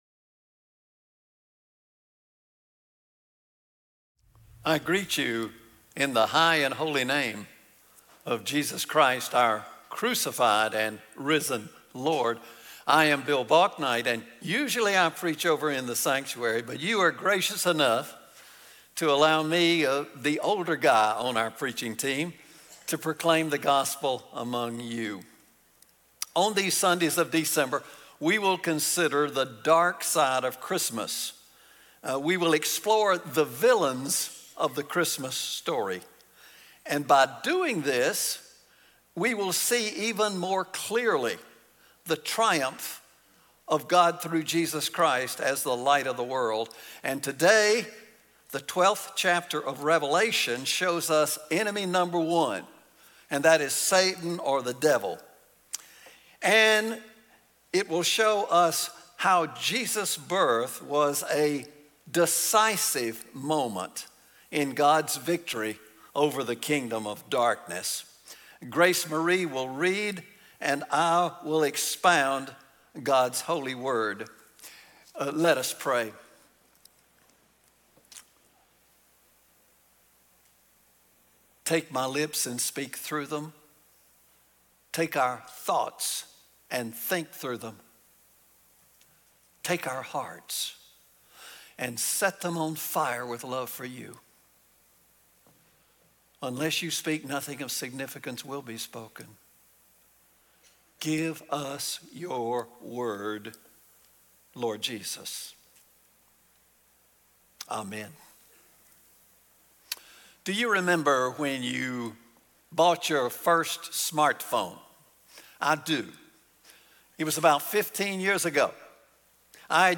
Audio: Sermons and features from Mt. Horeb United Methodist Church in Lexington, SC.